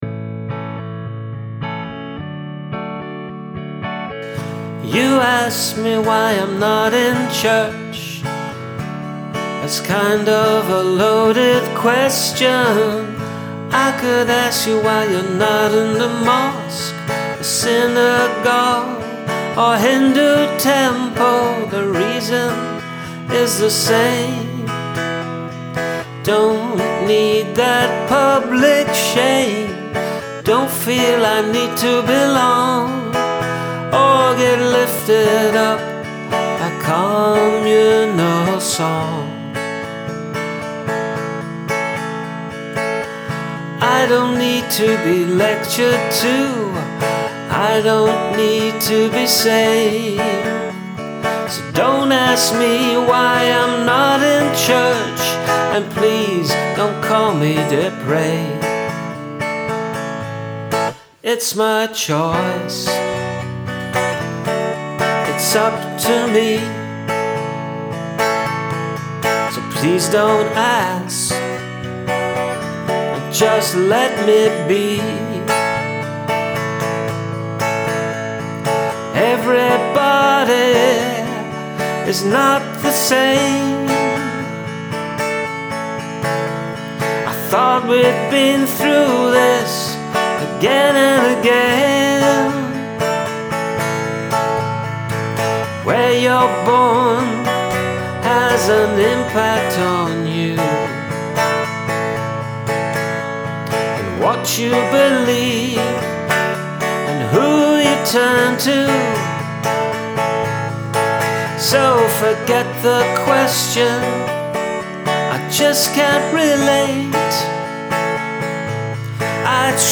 Very tuneful and really like the 'I don’t need to be lectured to I don’t need to be saved So don’t ask me why I’m not in church And please don’t call me depraved' stanza i